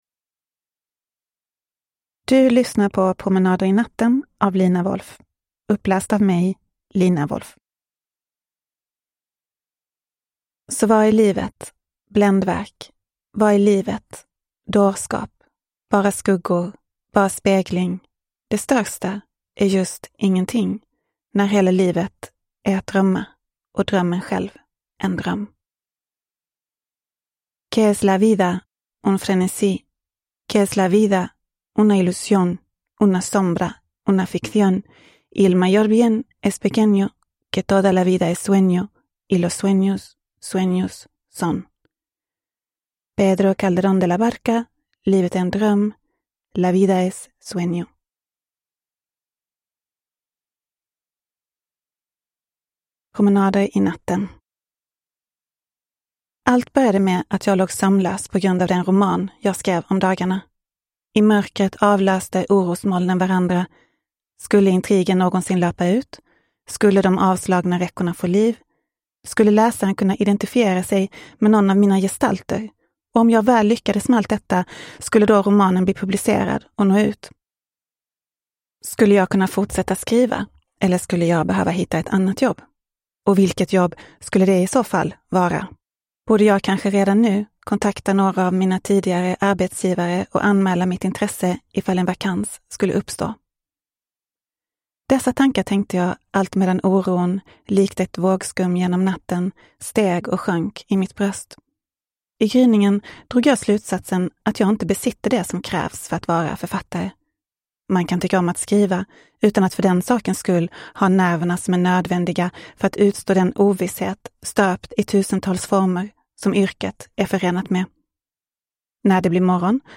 Promenader i natten – Ljudbok
Uppläsare: Lina Wolff